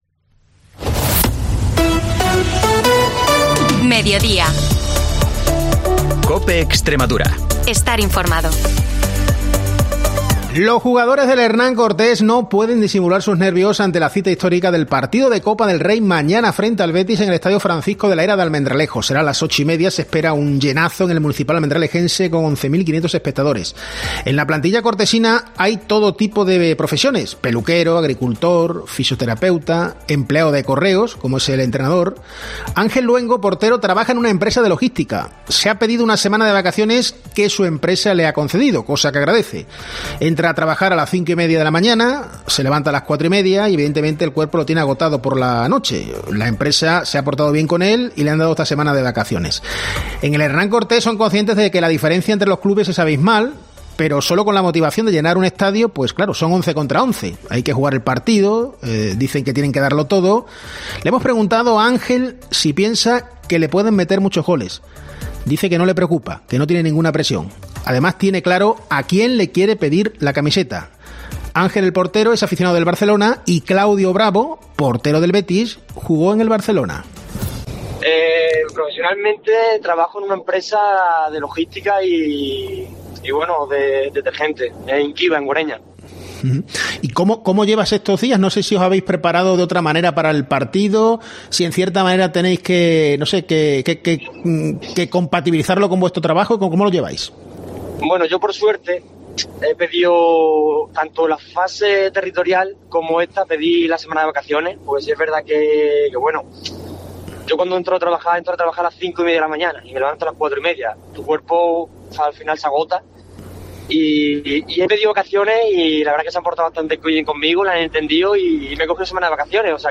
AUDIO: Información y entrevistas de Almendralejo-Tierra de Barros y Zafra-Río Bodión, de lunes a jueves, de 13.50 a 14 horas